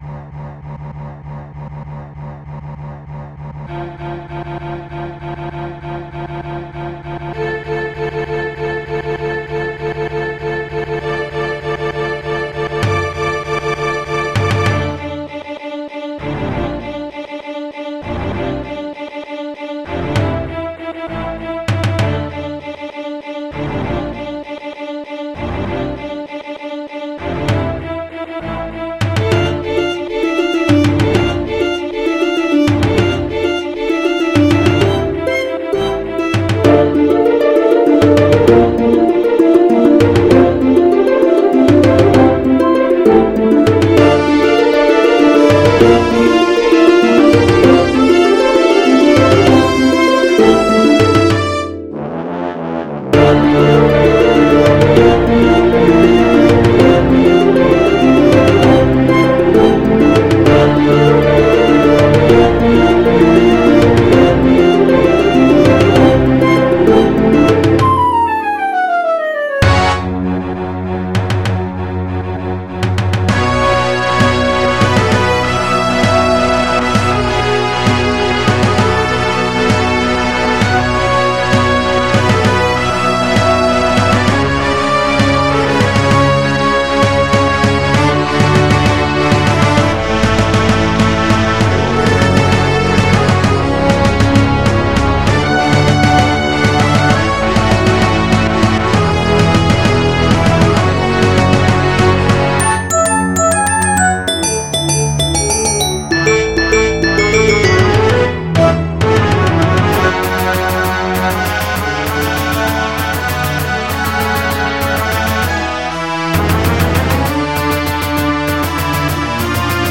MP3 (Converted)
this is only very rough cause some bits are missing